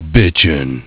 Worms speechbanks
Dragonpunch.wav